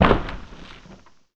DEMOLISH_Short_03_mono.wav